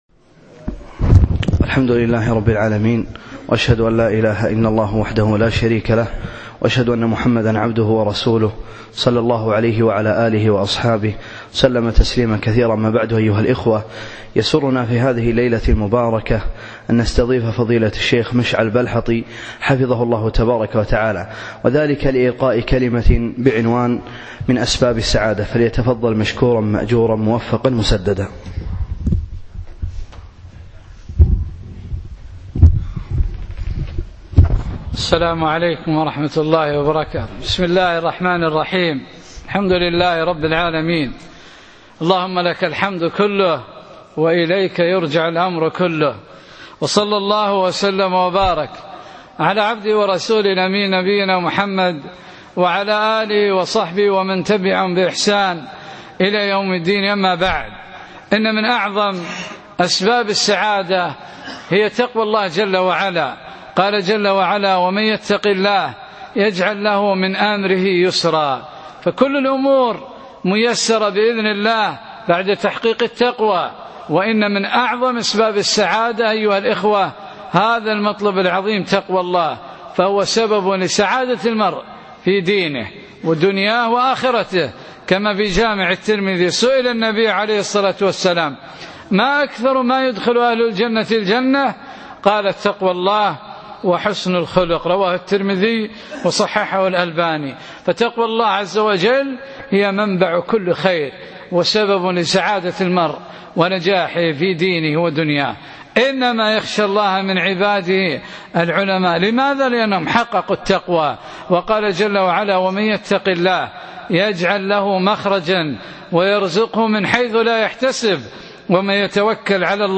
أقيمت المحاضرة بعد مغرب الأربعاء 4 3 2015 في مسجد نعيم بن عبدالله الفردوس